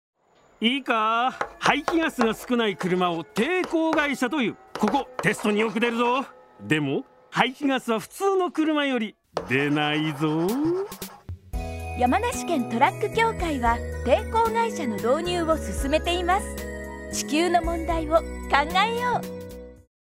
ラジオコマーシャル